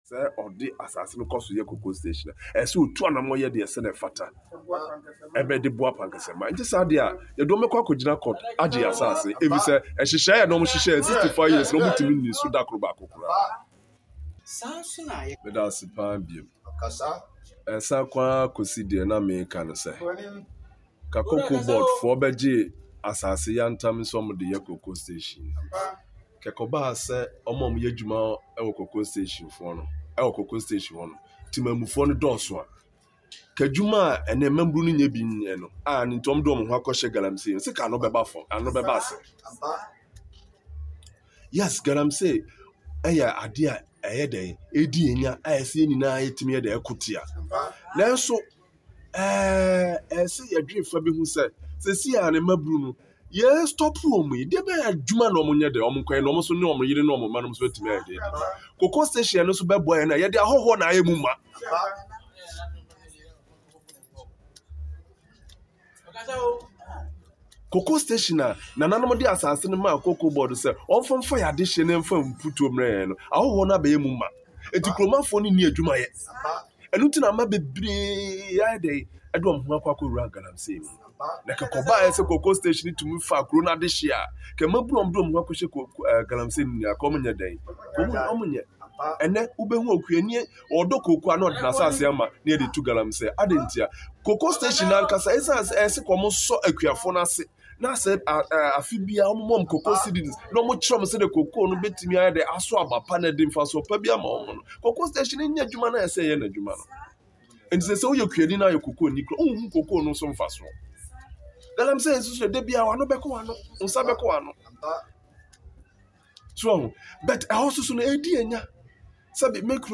According to Nana Dr. Boakye Darkwa in an exclusive interview with the media at the palace of Pankese in the Brim North district in eastern region, he said land was released to Ghana Cocoa Board with an agreement of offering jobs to the people of Pankese and Mponua catchment area and to help provide development to the people but not even a single developmental projects can be pointed which was initiated by Cocoa Board.